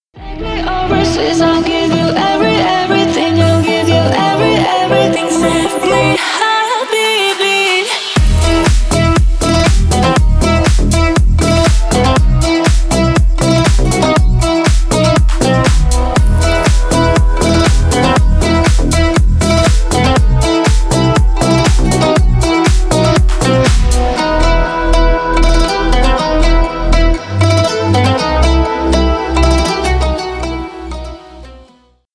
Ремикс # Поп Музыка
клубные